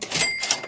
cash.ogg